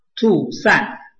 臺灣客語拼音學習網-客語聽讀拼-海陸腔-單韻母
拼音查詢：【海陸腔】tu ~請點選不同聲調拼音聽聽看!(例字漢字部分屬參考性質)